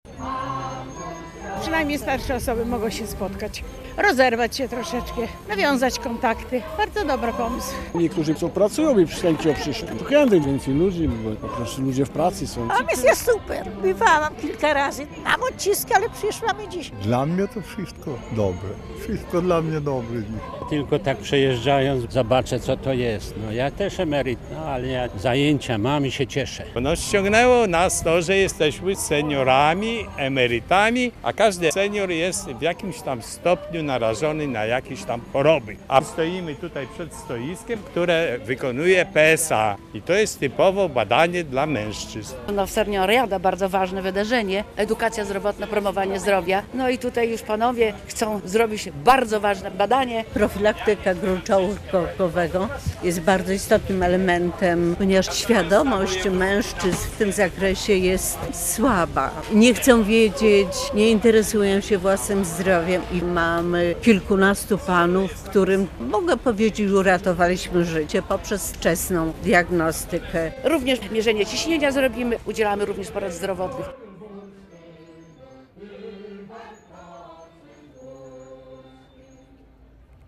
Seniorada w Łomży - relacja